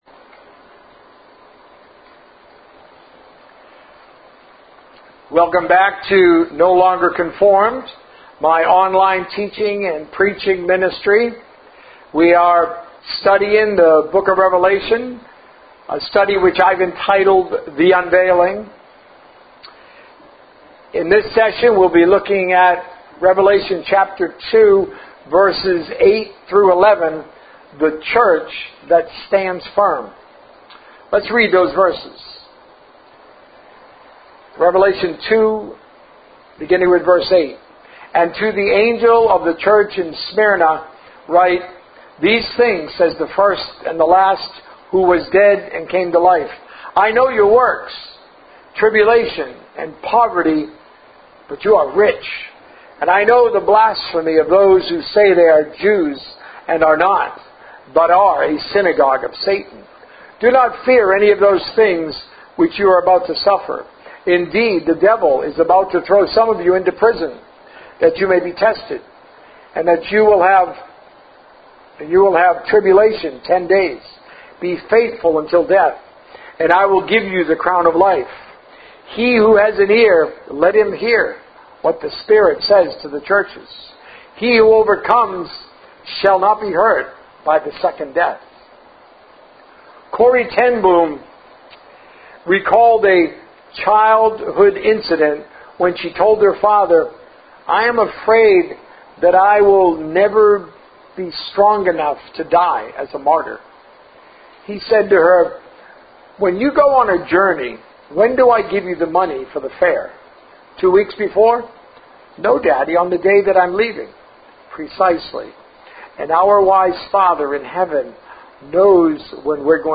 A message from the series "The Unveiling." The Authority of the Message